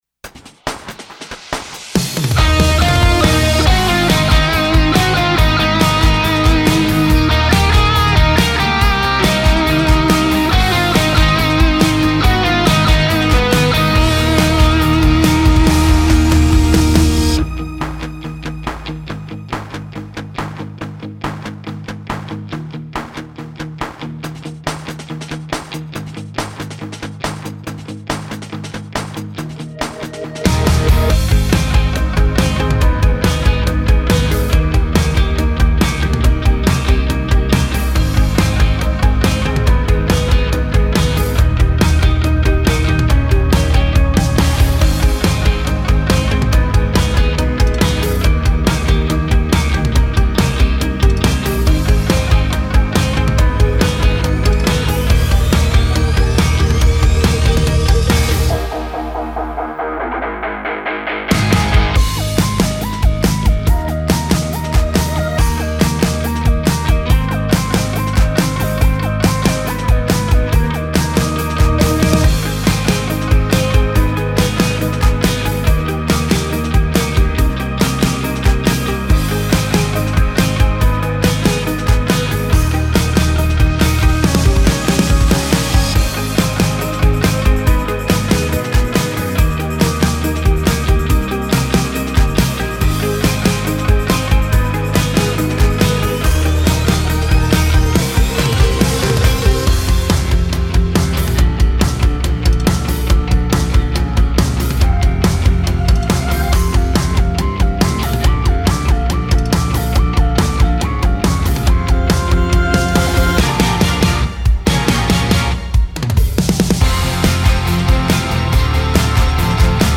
Характер песни: весёлый.
Темп песни: быстрый.
• Минусовка